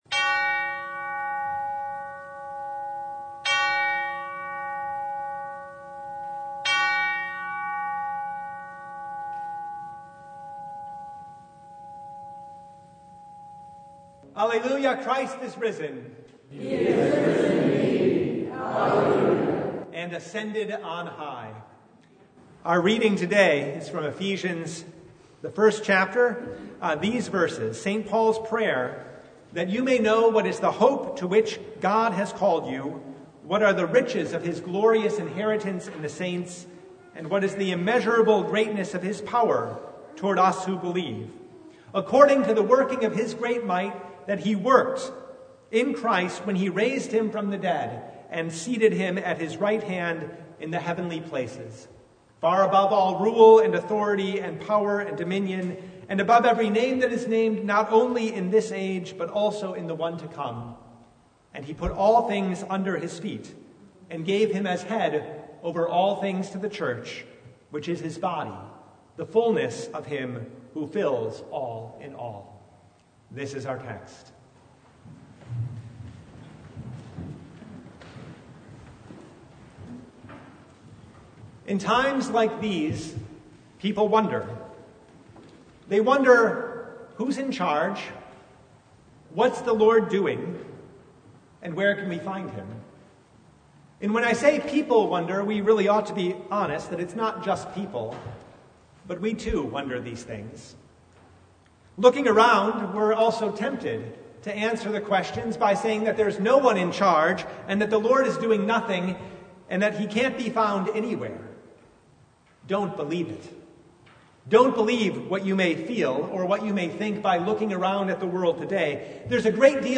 Service Type: The Feast of the Ascension of Our Lord